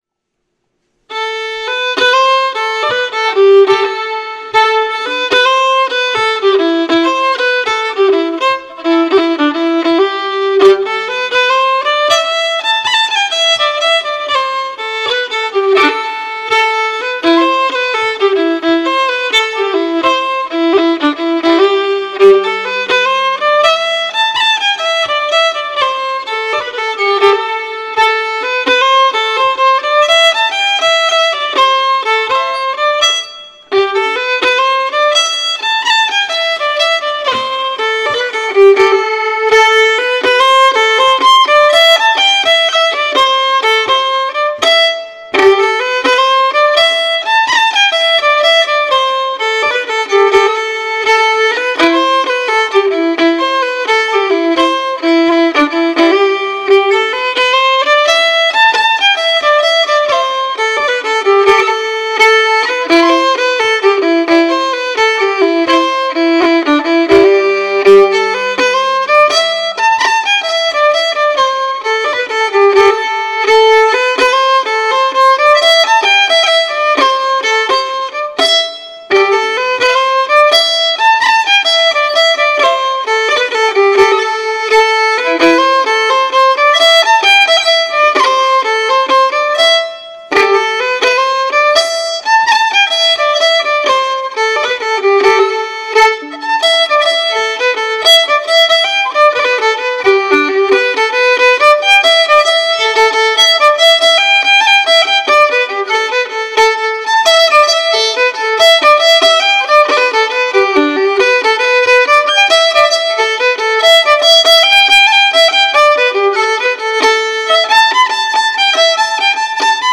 It promotes traditional Cape Breton style music through fiddle, guitar, piano, singers, step dancers, and lovers of Cape Breton Fiddle Music.
A Minor Jigs